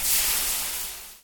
fizz.ogg